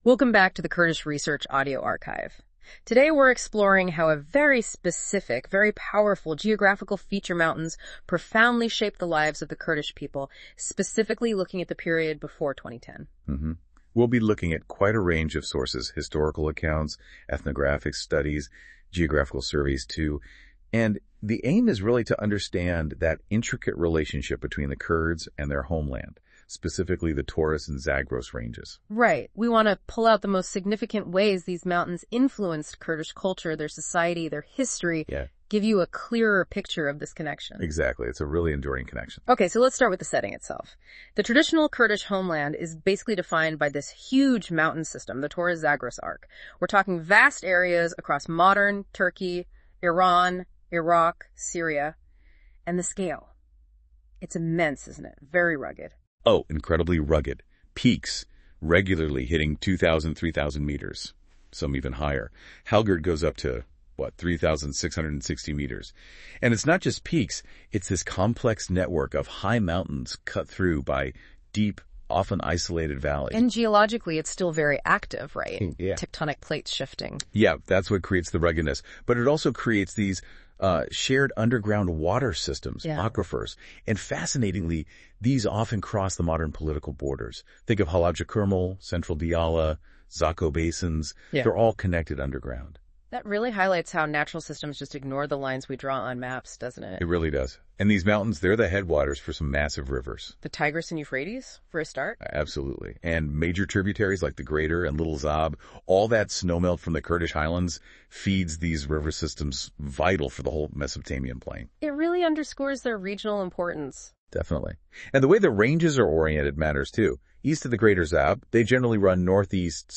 Note: This was made with AI research and AI audio output, and does not conform to academic standards.